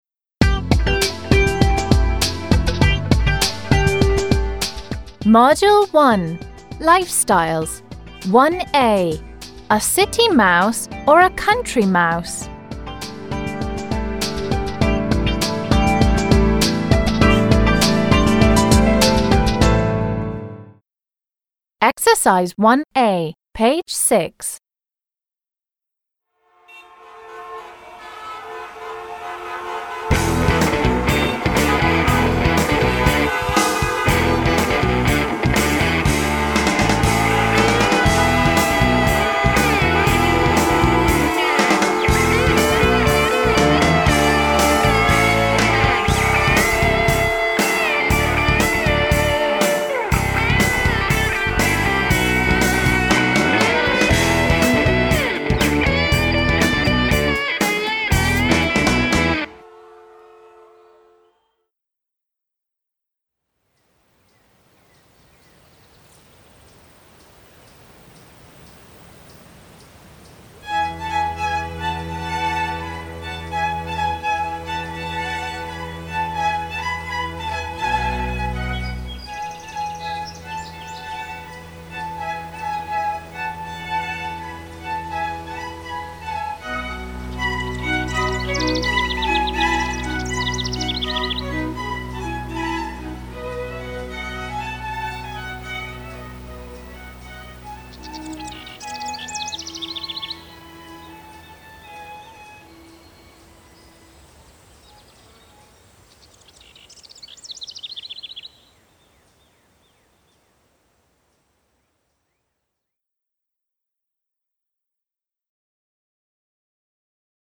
I hear loud car horns beeping everywhere.
I hear a soft violin playing and birds singing sweetly.